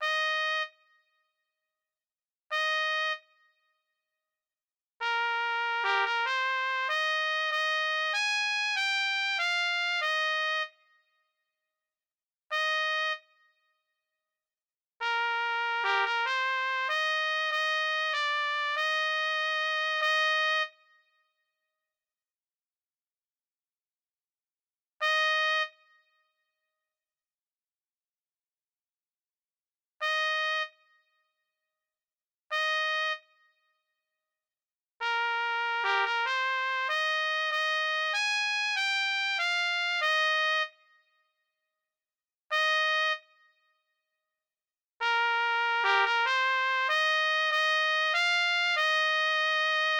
D=Counter-melody/Harmony/Bass Part-for intermediate to experienced players